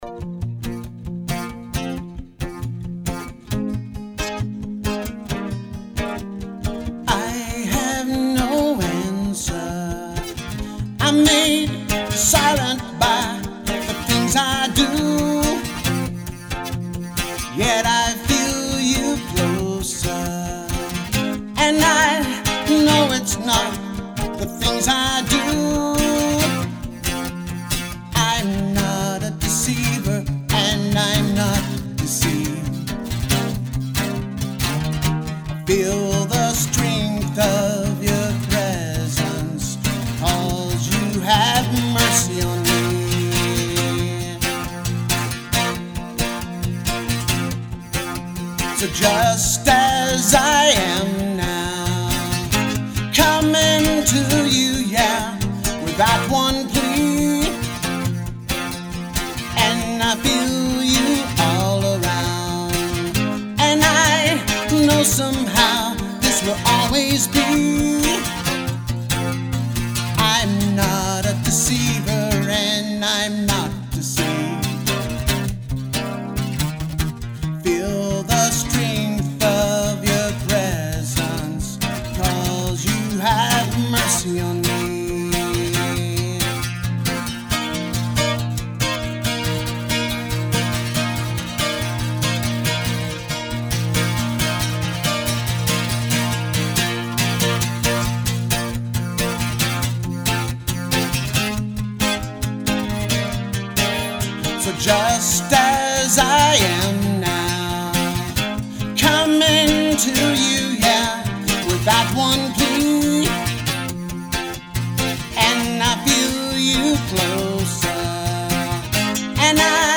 ORIGINAL ACOUSTIC SONGS
BASS
A CHRISTIAN SONG ABOUT THE MERCY OF THE LORD
MERCY_ON_ME_ACOUSTIC_GOOD.mp3